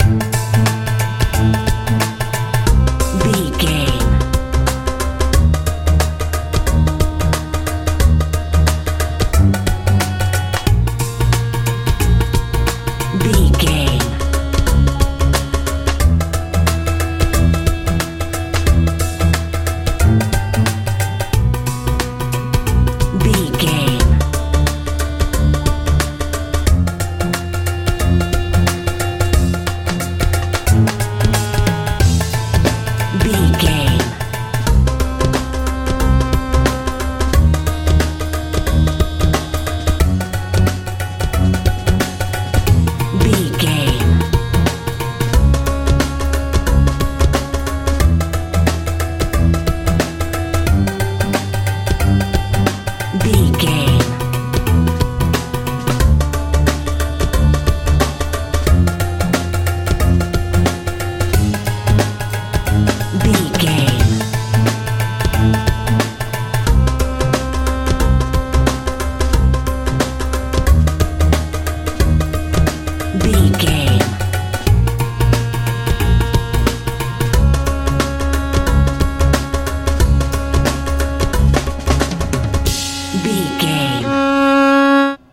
bollywood feeling music
Ionian/Major
G♭
violin
synthesiser
bass guitar
drums
dramatic
groovy
lively